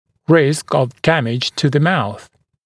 [rɪsk əv ‘dæmɪʤ tə ðə mauθ][риск ов ‘дэмидж ту зэ маус]риск травмирования рта